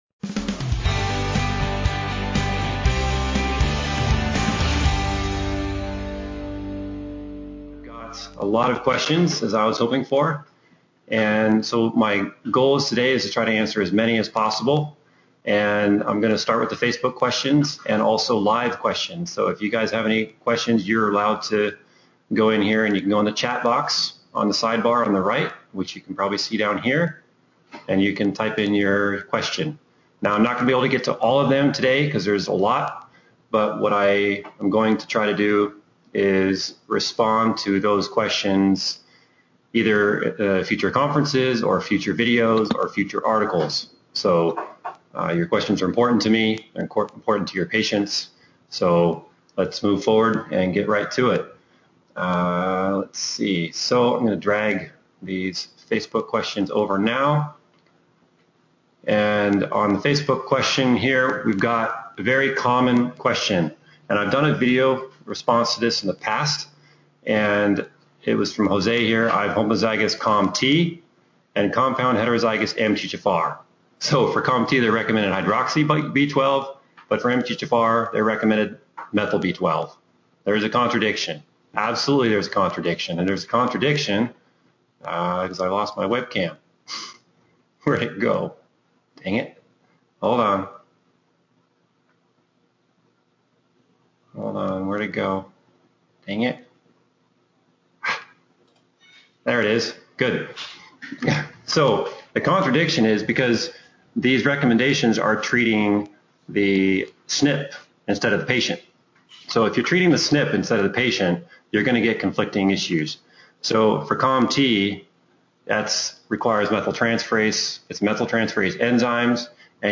Q & A Webinar